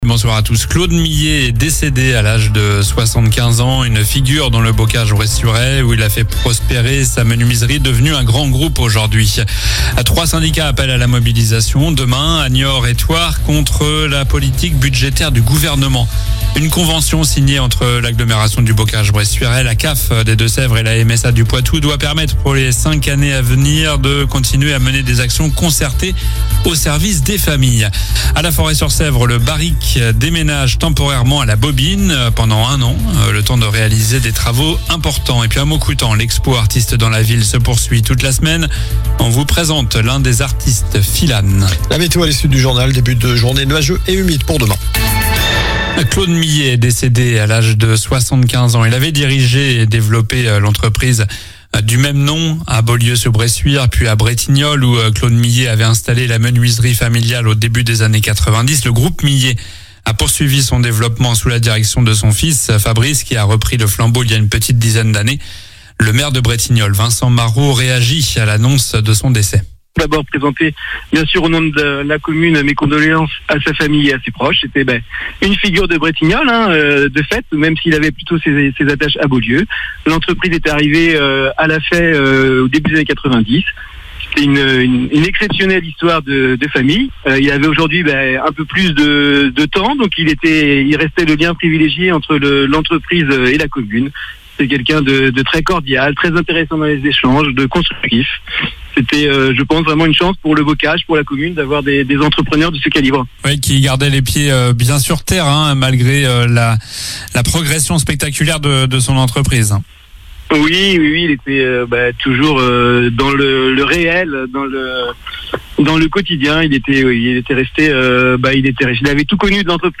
Journal du lundi 1er décembre (soir)